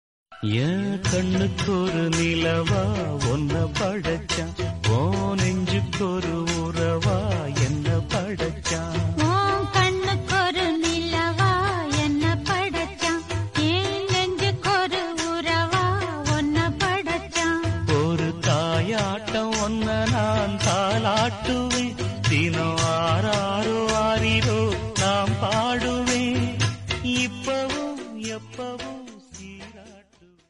tamil ringtoneemotional ringtonelove ringtonemelody ringtone
best flute ringtone download